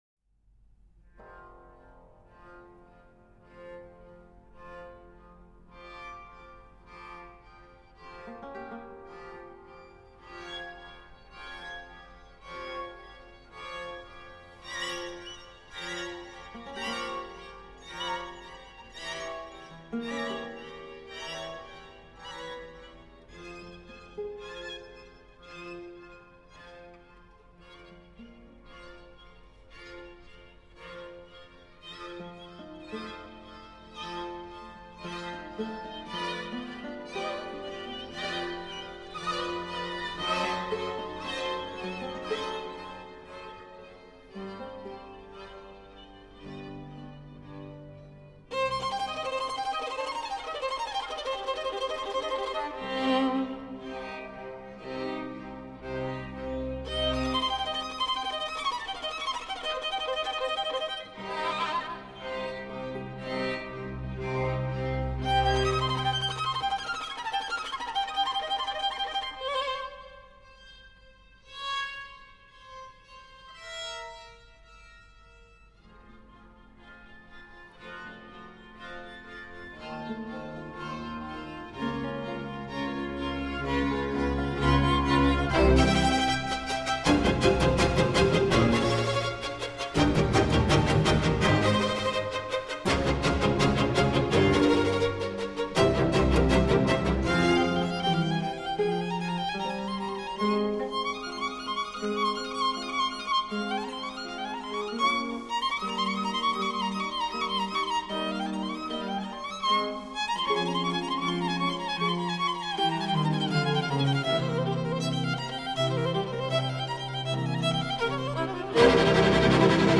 Escoita este exemplo das "Catro estacións: Inverno" de Vivaldi, e observa como a intensidade vai mudando en toda a peza, o que lle confire una sonoridade especial.